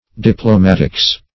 Meaning of diplomatics. diplomatics synonyms, pronunciation, spelling and more from Free Dictionary.